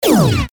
Paradise/sound/weapons/pulse3.ogg
Added new weapon sounds: blaster, laser, pulse, wave, emitter, and one for the marauder canon.